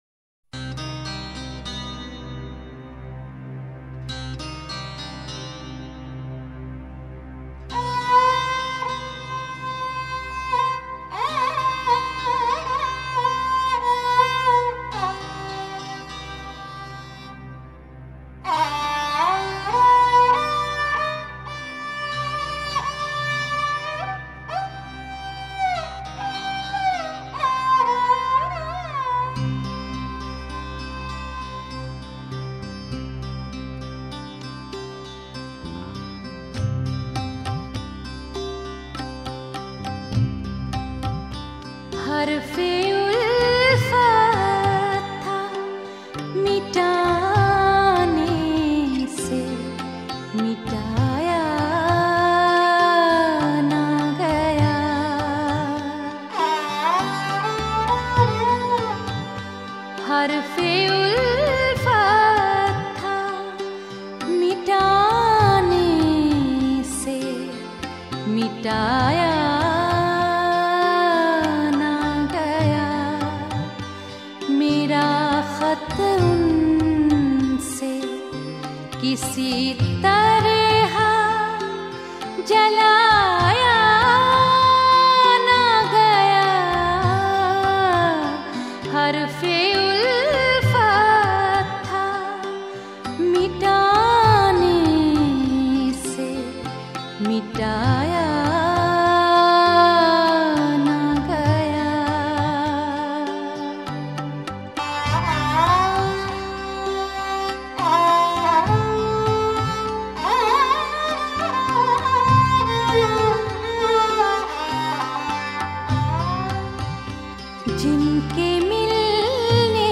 Ghazal